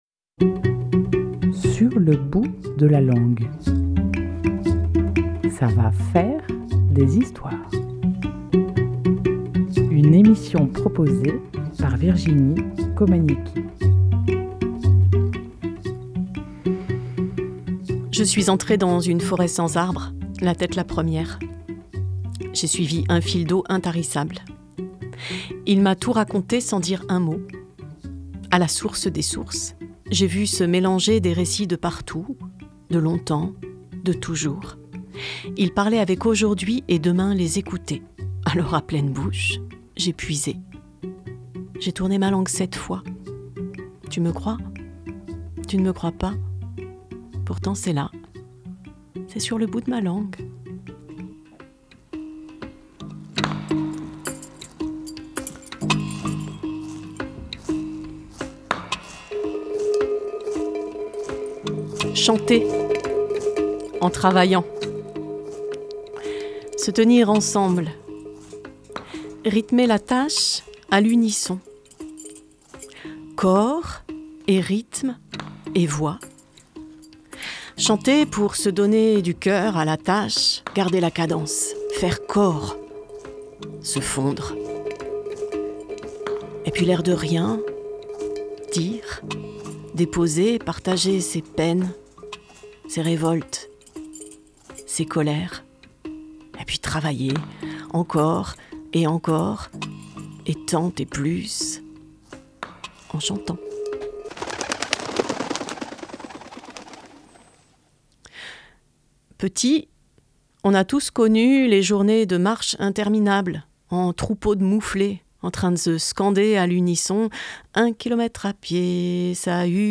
Chant de pagayeurs des îles salomon
Work Hammer song